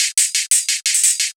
Index of /musicradar/ultimate-hihat-samples/175bpm
UHH_ElectroHatB_175-01.wav